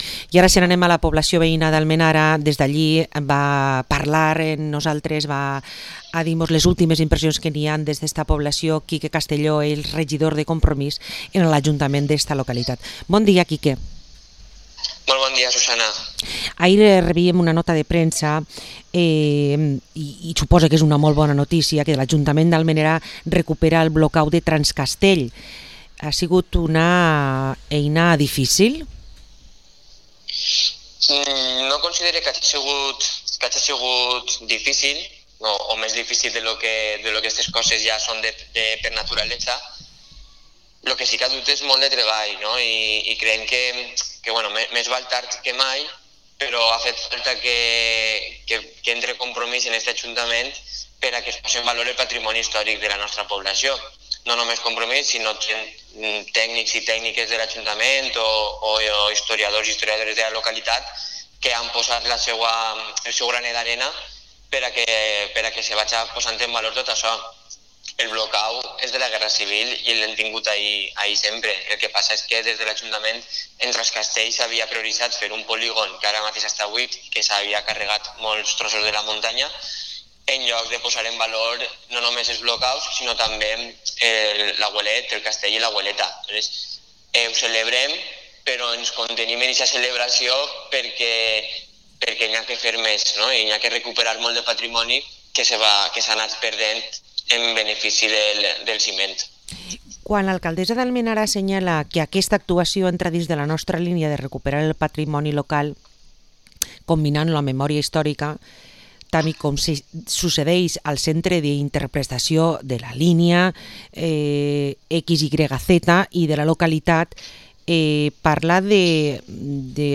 Entrevista a Quique Castelló, concejal de Compromís en el Ayuntamiento de Almenara